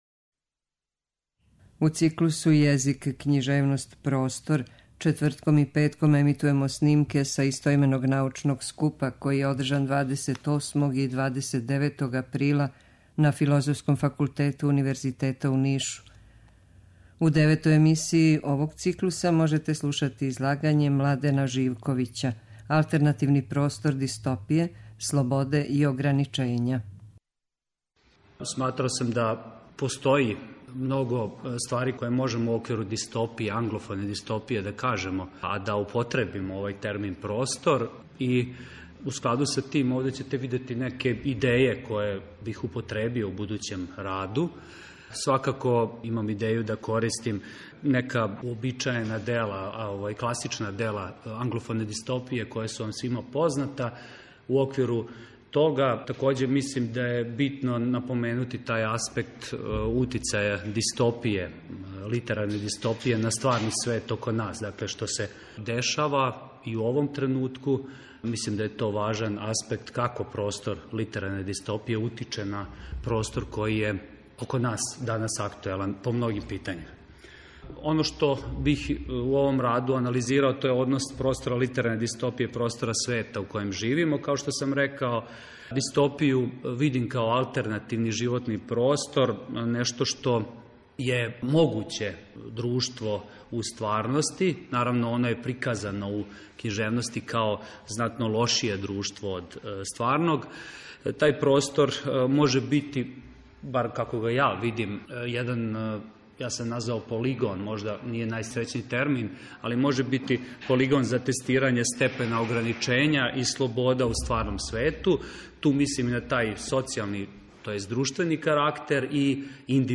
У циклусу ЈЕЗИК, КЊИЖЕВНОСТ, ПРОСТОР четвртком и петком ћемо емитовати снимке са истoименог научног скупа, који је одржан 28. и 29. априла на Филозофском факултету Универзитета у Нишу.